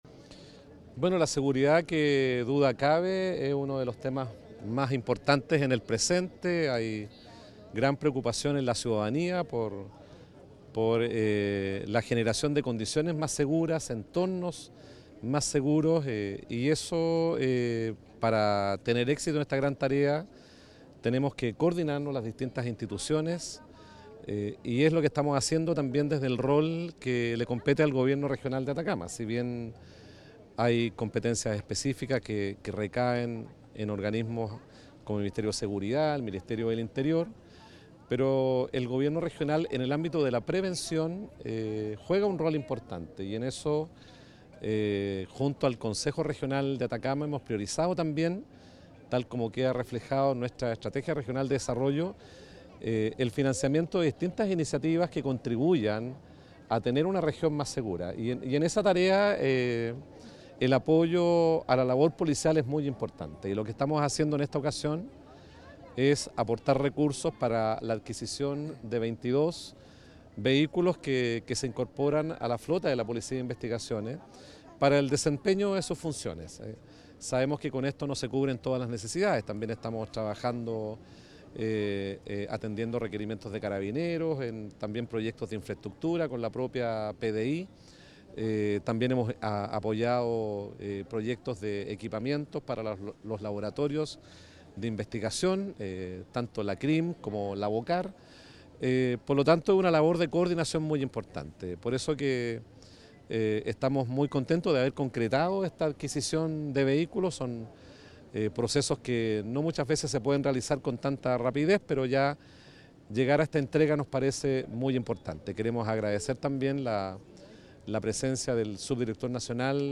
GOBERNADOR-Vehiculos-PDI.mp3